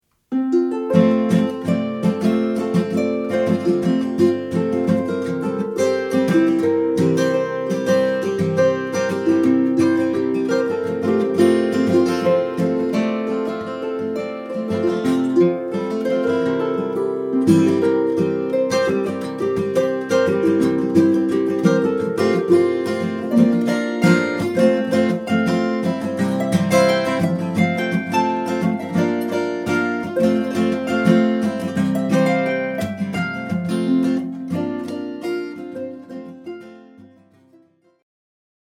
South American/Latin or tunes from other exotic places